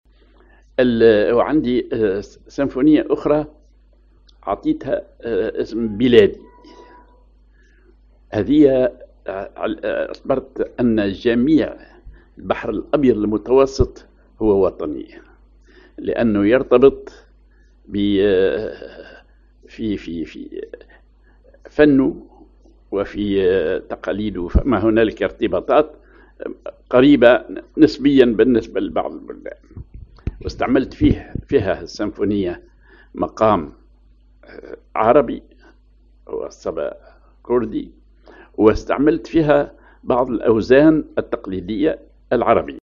كردي على النوا
سمفونيّة
معزوفة من نوع الكنسرتو
كنسرتو للكمنجاة والأركستر